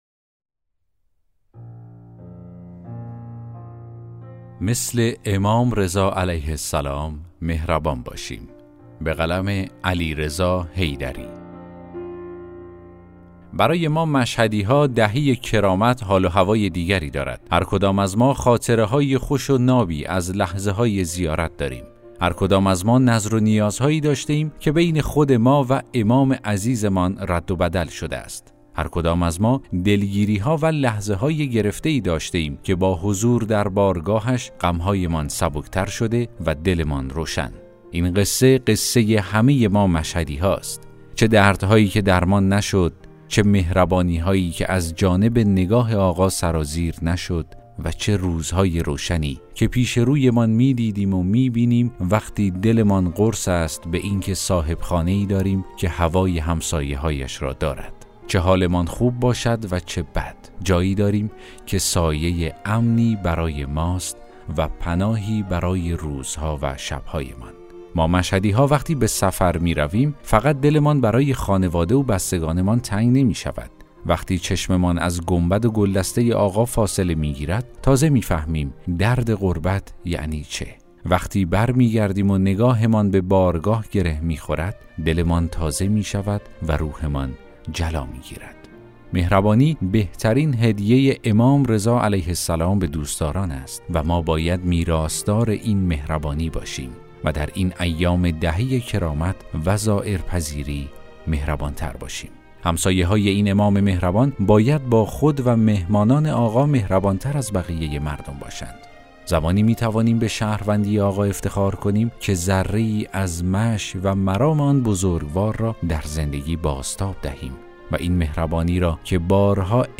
داستان صوتی: مثل امام رضا (ع) مهربان باشیم